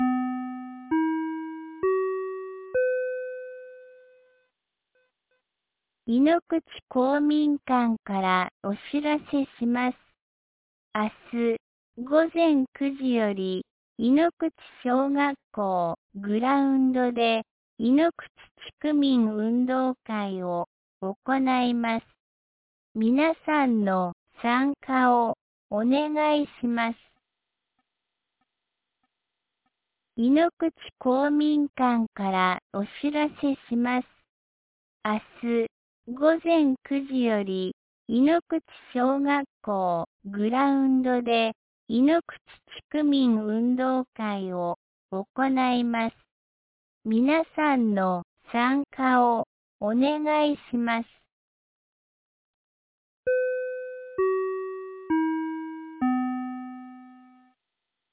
2025年10月11日 17時31分に、安芸市より井ノ口へ放送がありました。